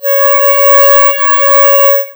plt.title('Spectrum of Flute A4')
Apply same procedure to get pitch shift